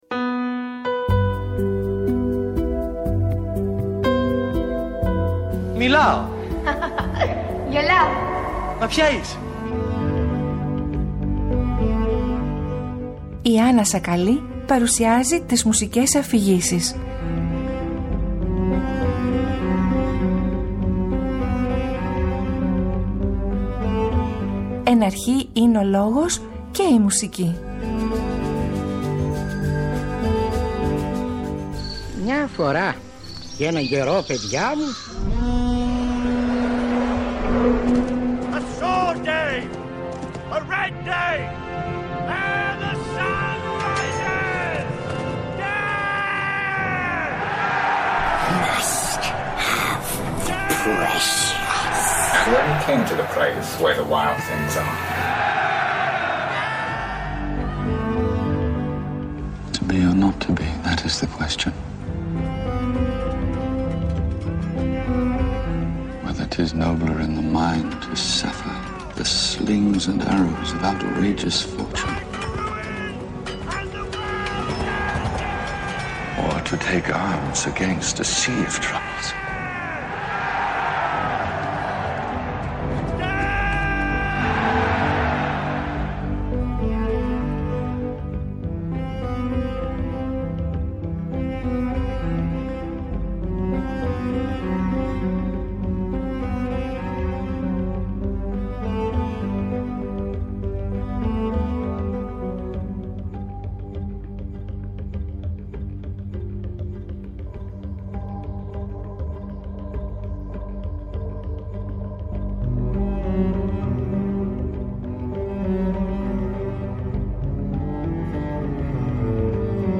Η συζήτηση πλαισιώνεται μουσικά με χαρακτηριστικά αποσπάσματα από την Όπερα.